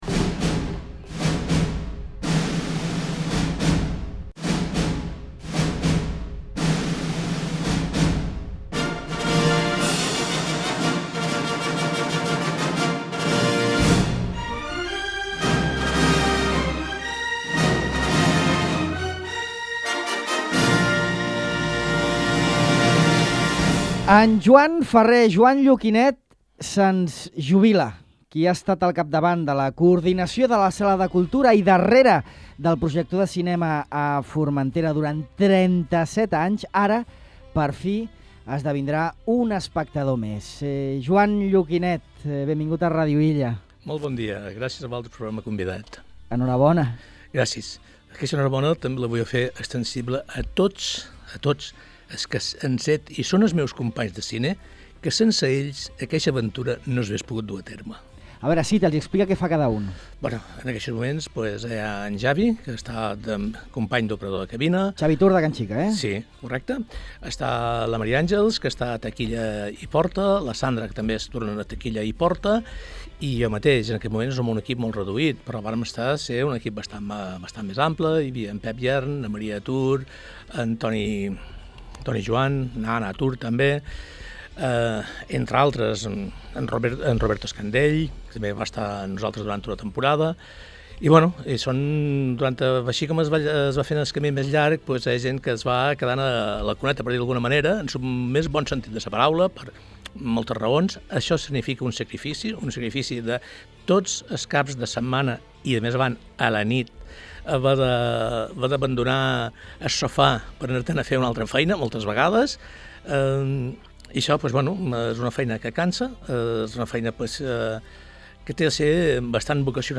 En aquesta entrevista a Ràdio Illa rememora els inicis de la seva vocació en el món del cinema i reconeix la tasca que han portat a terme els seus col·laboradors al llarg de gairebé quatre dècades. A més, repassa l’evolució que ha experimentat la tecnologia del cine, amb els formats de cel·luloide de la primera sessió, el 20 de febrer de 1988, quan es projectà ‘La Misión’, fins als “freds” projectors digitals dels nostres dies.